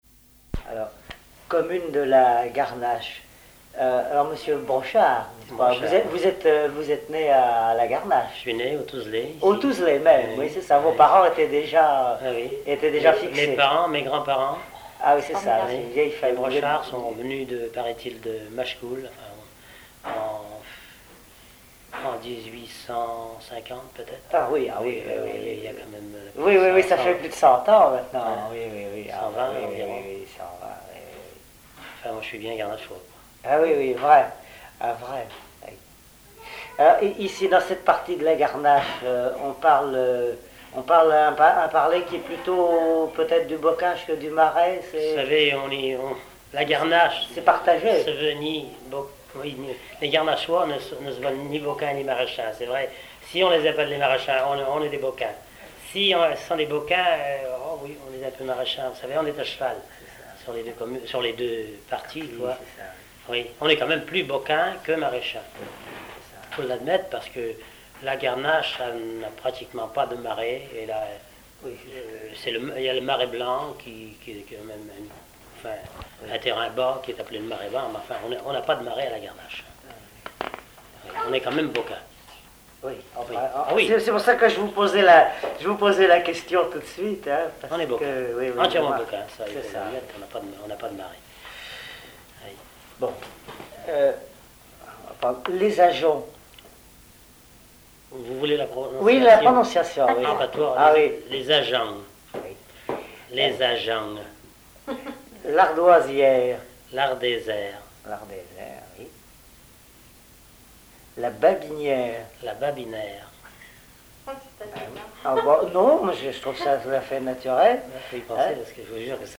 Noms de lieux-dits en patois maraîchin
Catégorie Témoignage